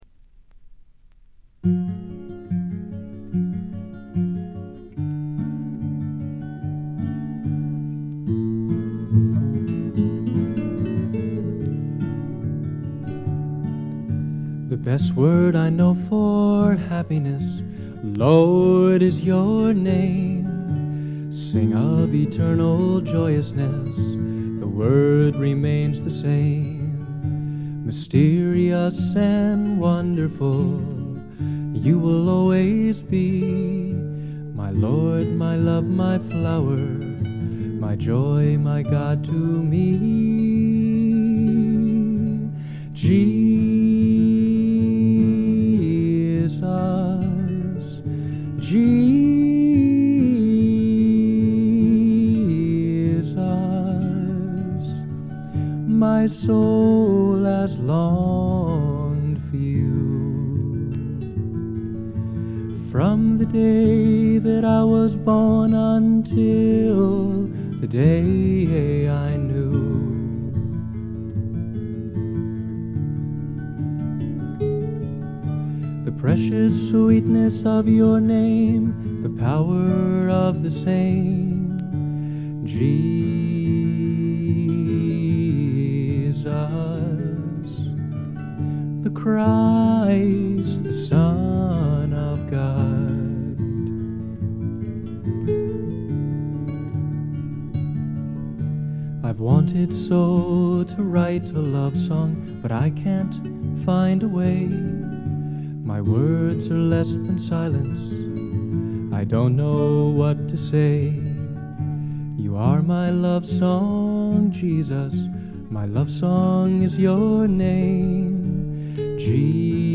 全編を通じて見事にサイレントで聖なる世界が展開します。
ずばりドリーミーフォークの名作と言って良いでしょう。
試聴曲は現品からの取り込み音源です。